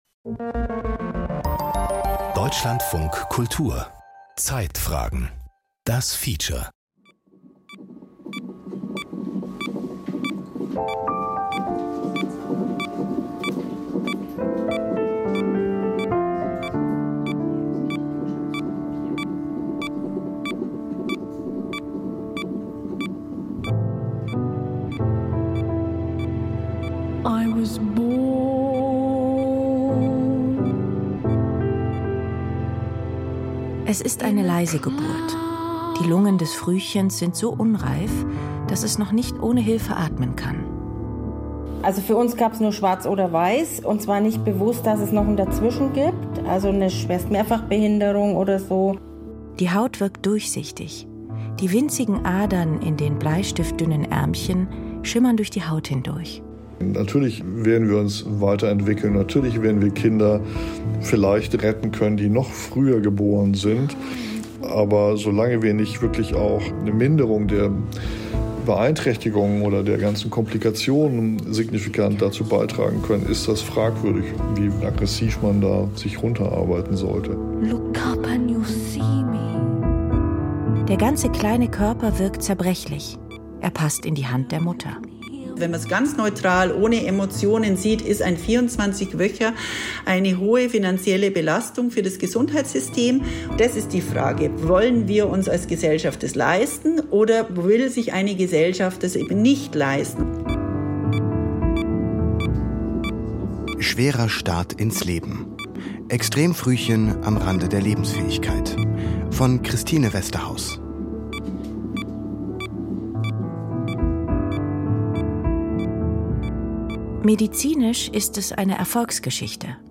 Zeitfragen Feature